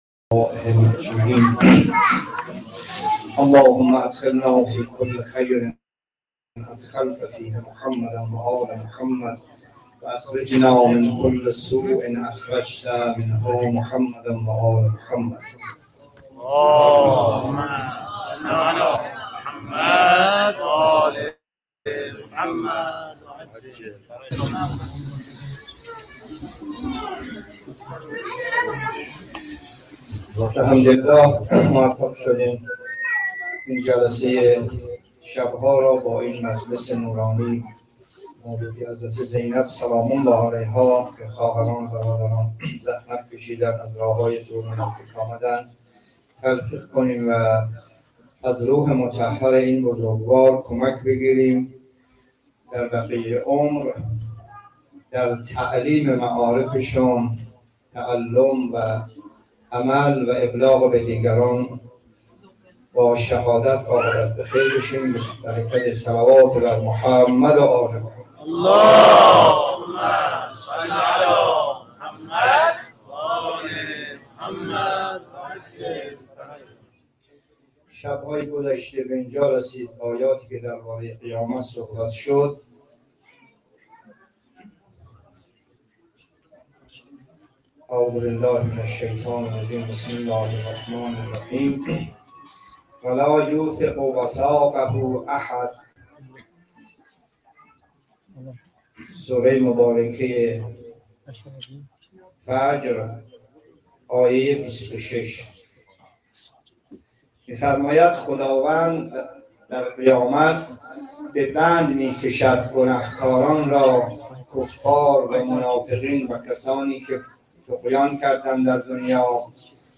جلسه تفسیر قرآن (22) سوره فجر
همزمان با شام ولادت حضرت زینب سلام‌الله‌علیها